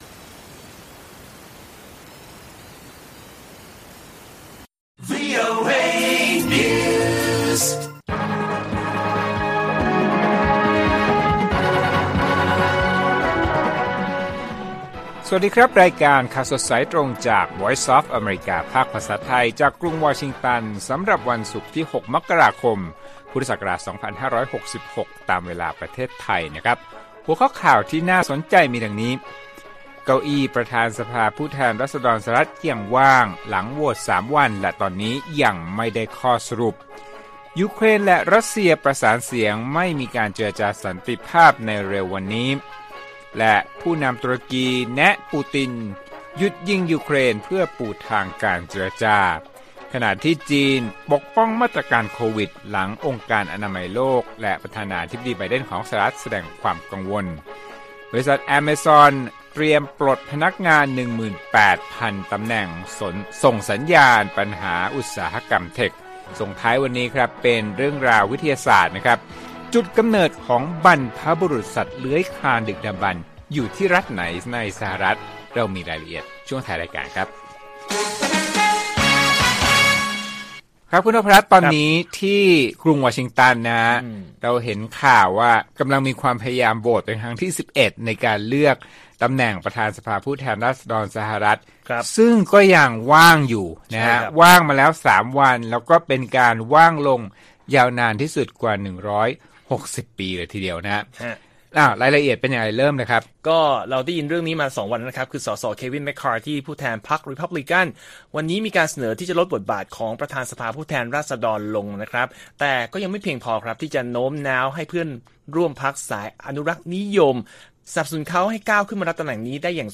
ข่าวสดสายตรงจากวีโอเอไทย ศุกร์ ที่ 6 ม.ค. 66